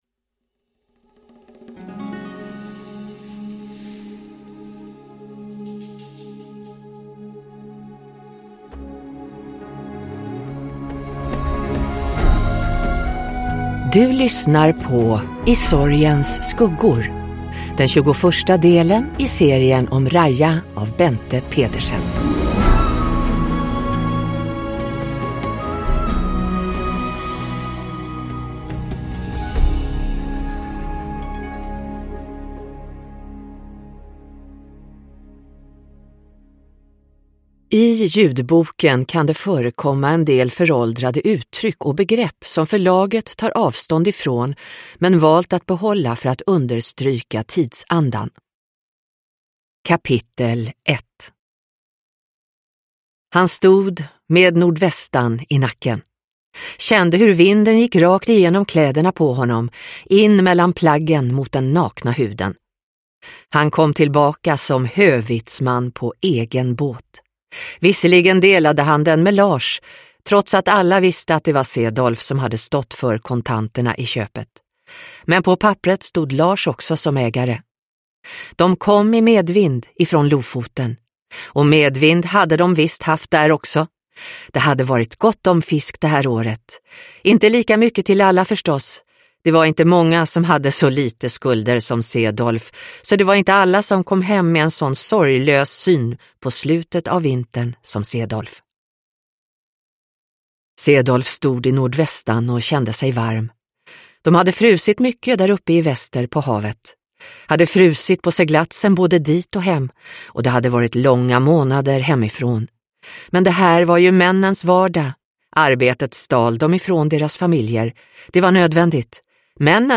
I sorgens skuggor – Ljudbok